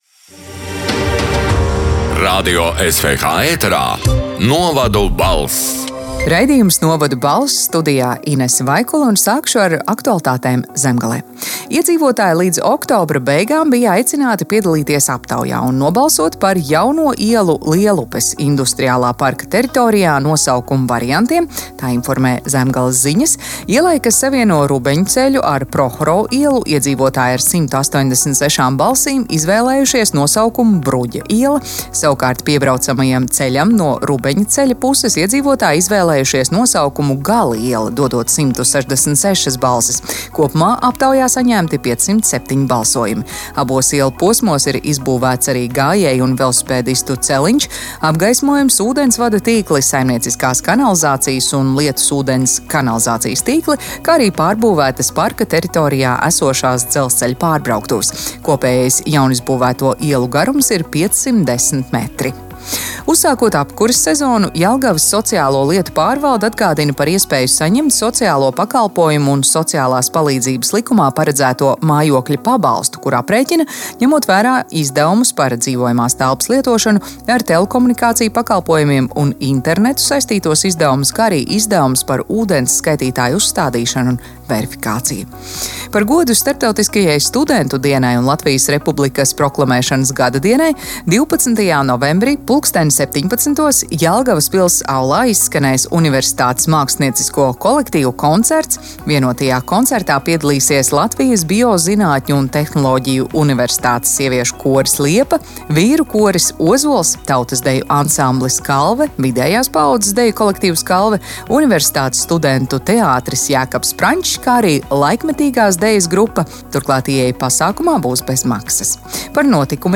“Novadu balss” 8. novembra ziņu raidījuma ieraksts: